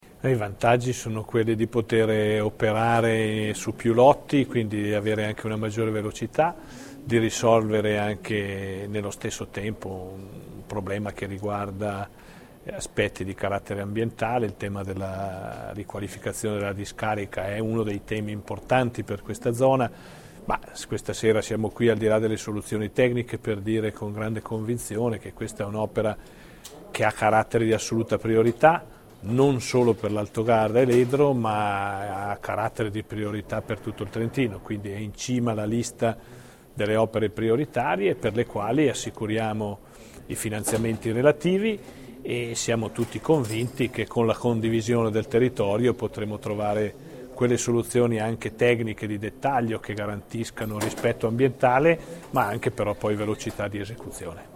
In Comunità l'incontro fra il presidente Rossi e l'assessore Gilmozzi con gli amministratori dell'Alto Garda